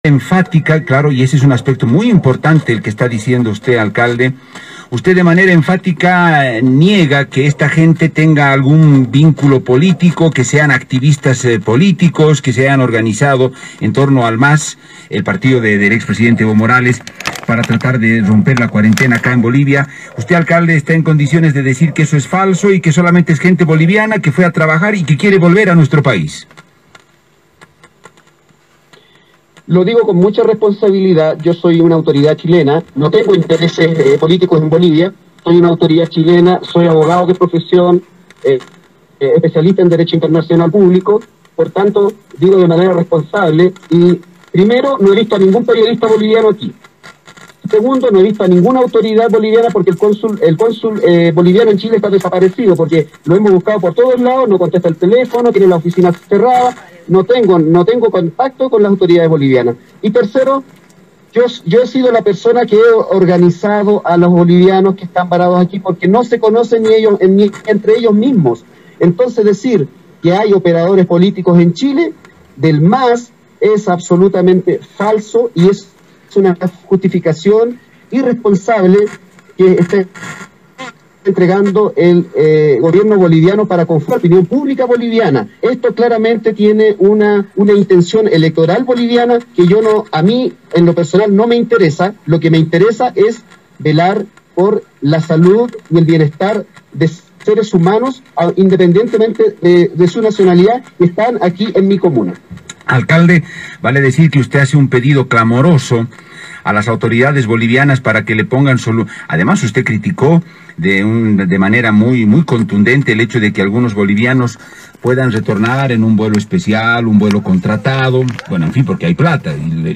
«Decir que hay operadores políticos del MAS es absolutamente falso y es una justificación irresponsable para confundir a la opinión pública boliviana. Esto tiene una intención electoral», dijo el alcalde de Colchane, Javier García en una entrevista con Radio Erbol
Javier García, alcalde de Colchane localidad chilena fronteriza con Bolivia